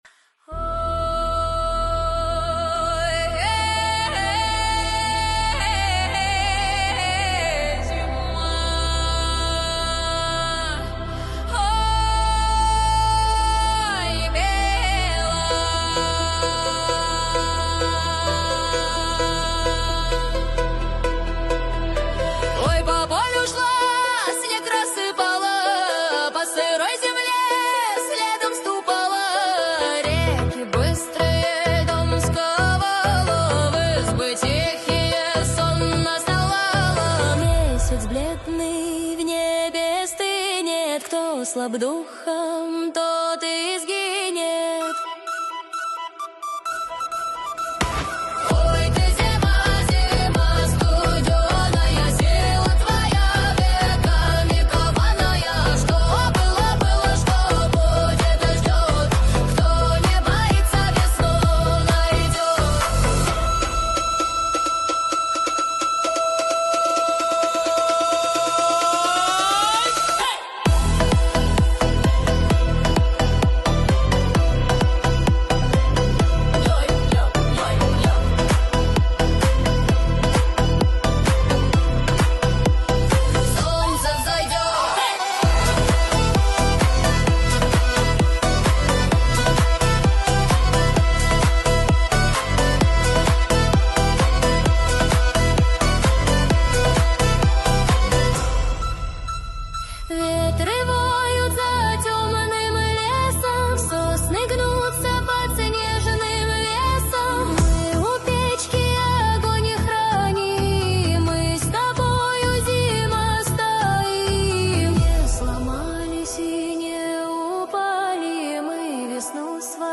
ИИ-хит 2026 в старославянском народном стиле.